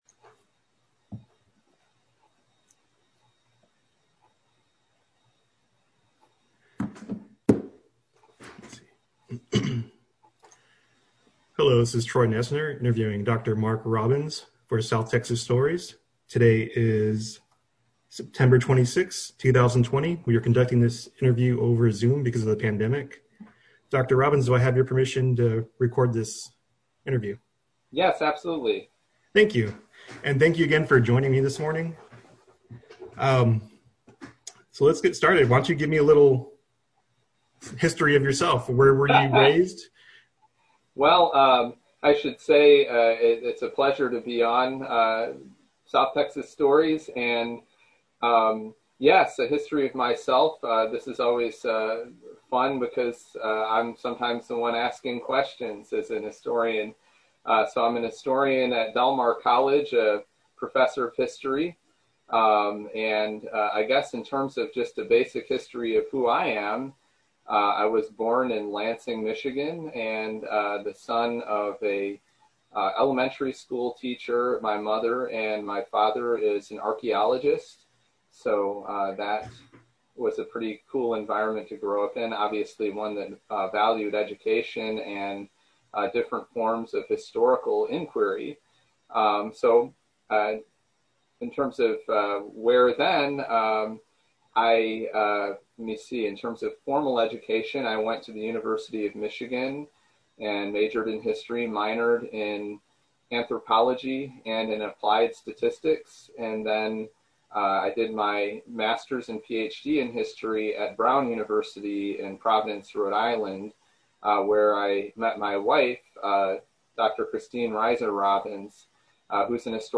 Oral History Interview
Corpus Christi, Texas, interview conducted over Zoom